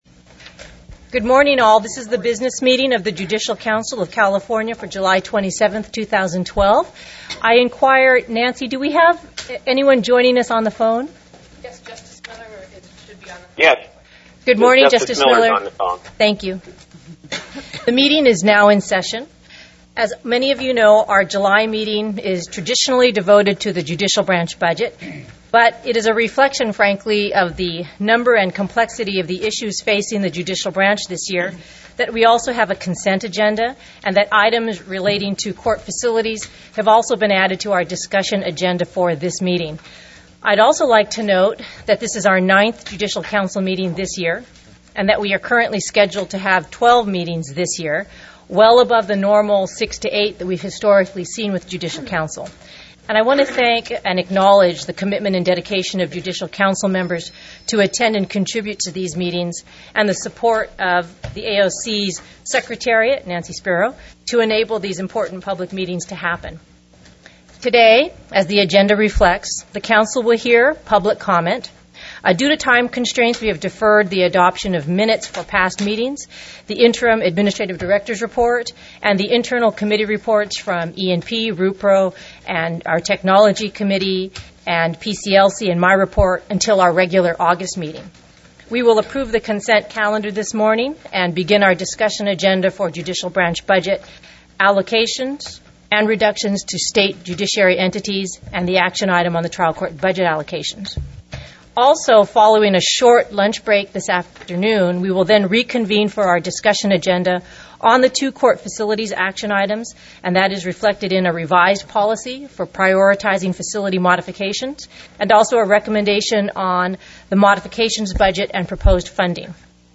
July 27, 2012 Judicial Council Meeting Public Meeting Audio Archive (MP3)